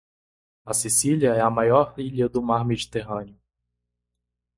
Pronounced as (IPA) /ˈi.ʎɐ/